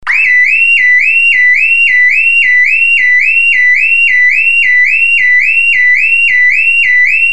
Звуки звонка, будильника
Громкий будильник Сигнализация 2 вариант раздражающий